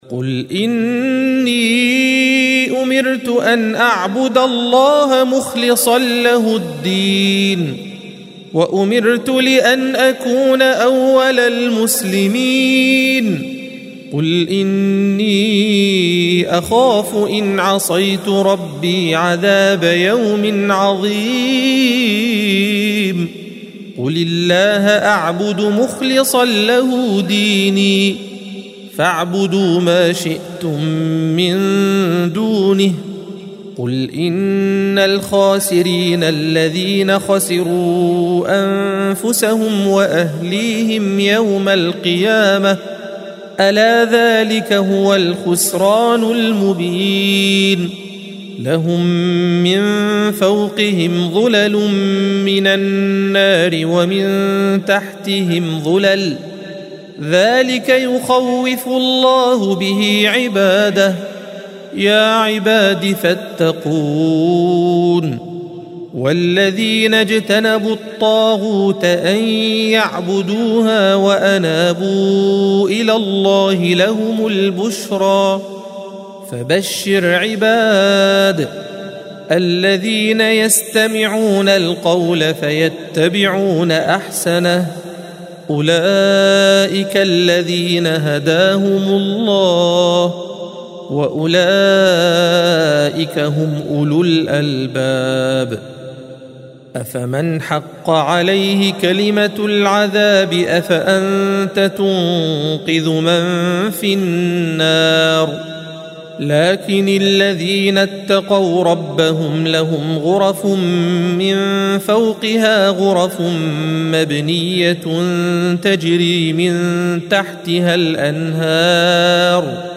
الصفحة 460 - القارئ